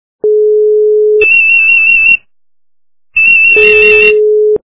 » Звуки » Природа животные » Писк - москитов
При прослушивании Писк - москитов качество понижено и присутствуют гудки.
Звук Писк - москитов